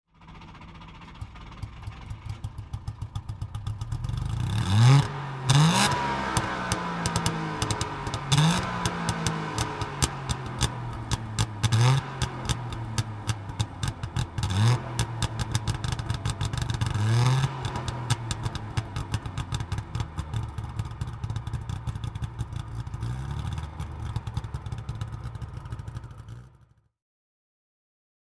Auto Union 1000 SP (1962) - Starten und Leerlauf